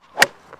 golf_swing.wav